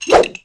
wrench_swipe3.wav